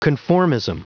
Prononciation du mot conformism en anglais (fichier audio)
Prononciation du mot : conformism